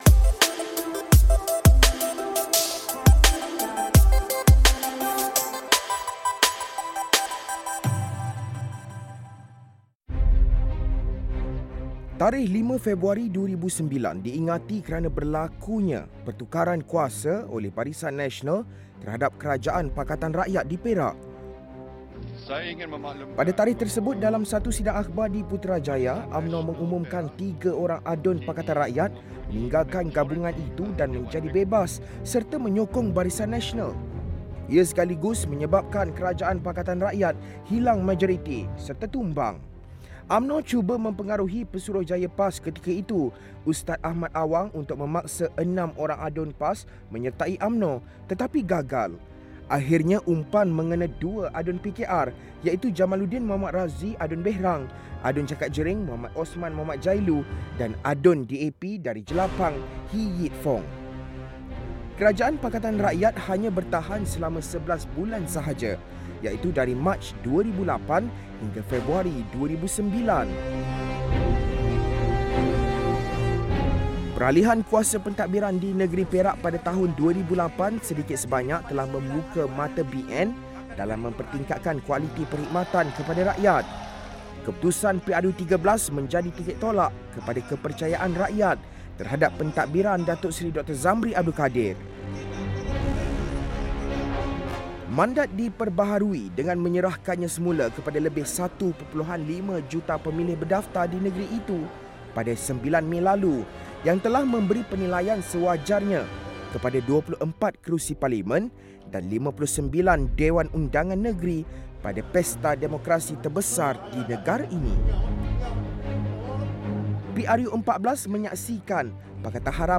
2:12 - Datuk Seri Dr Zambry Abdul Kadir, Pengerusi BN Perak 3:35 - Datuk Seri Mohd Annuar Zaini, Ahli Dewan Negara Perak